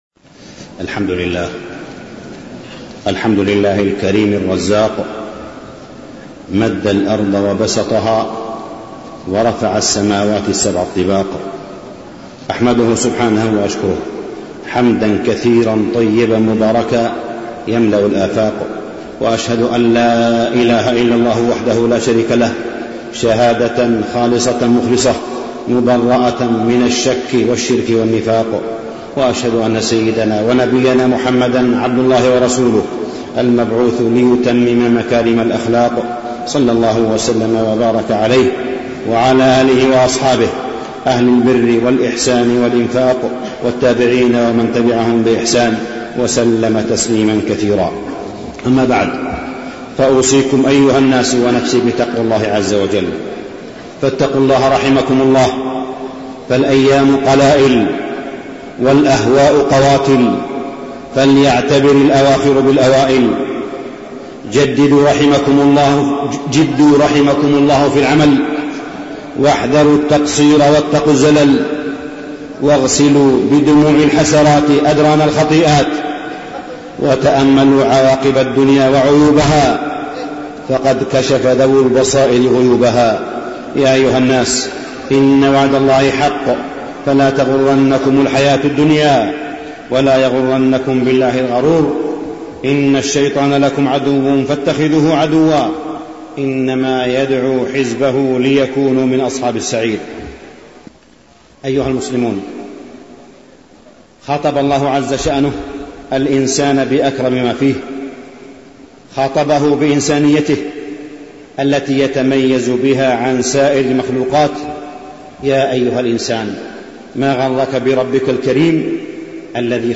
تاريخ النشر ١٦ محرم ١٤٢٩ هـ المكان: المسجد الحرام الشيخ: معالي الشيخ أ.د. صالح بن عبدالله بن حميد معالي الشيخ أ.د. صالح بن عبدالله بن حميد تكريم الإسلام لذوي الإحتياجات الخاصة